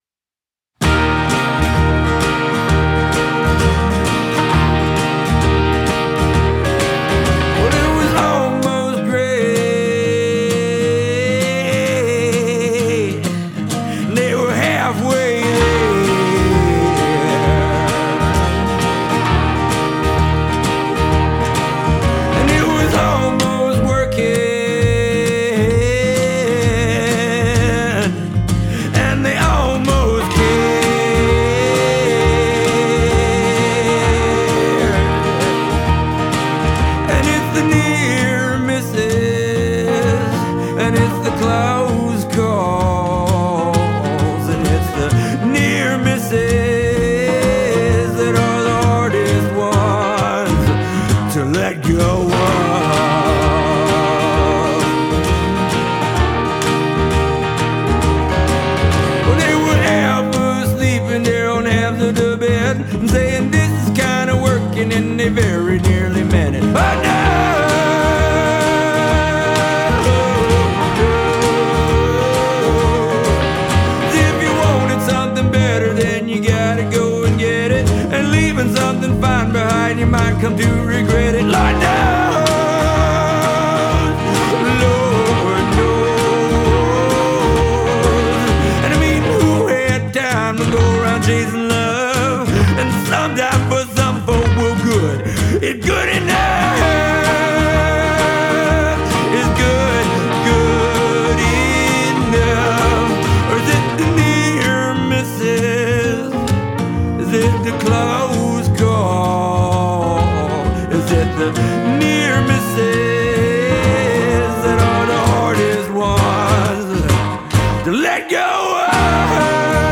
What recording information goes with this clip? performing live from the 103.3 AshevilleFM studio